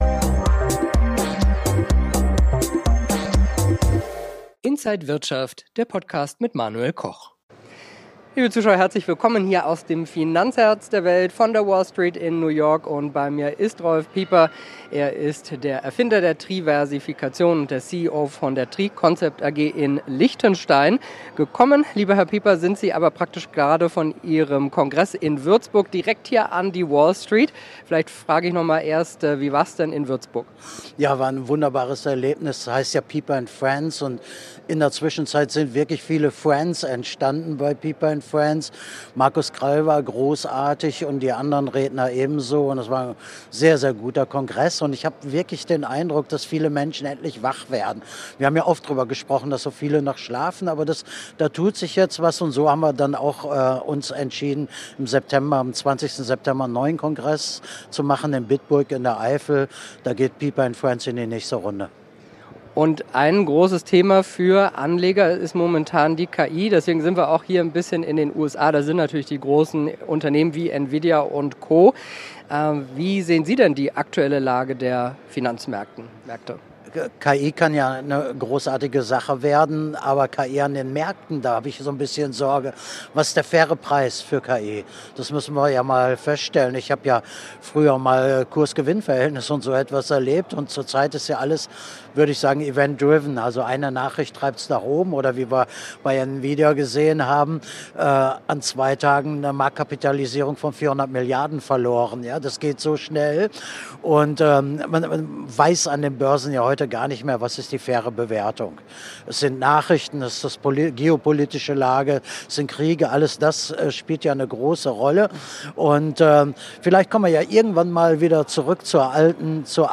Alle Infos im Interview und auf http